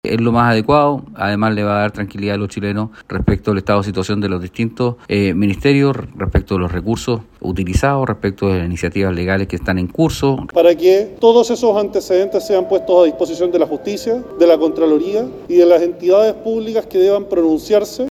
Ante este anuncio, los diputados Frank Sauerbaum (RN) y Luis Sánchez (Republicano) respaldaron la medida.